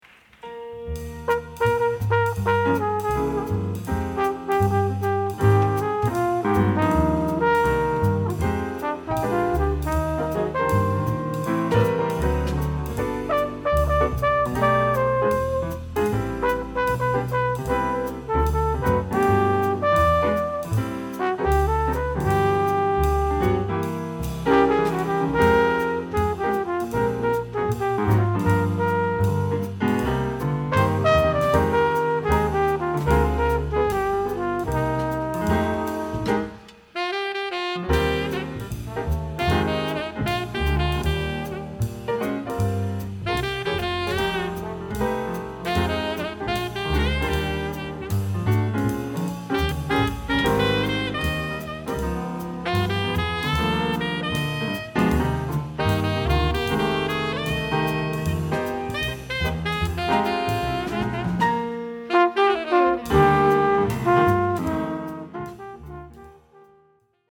Genres: Jazz, Live.